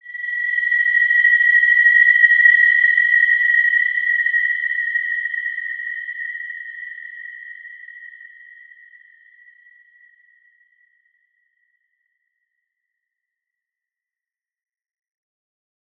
Wide-Dimension-B5-mf.wav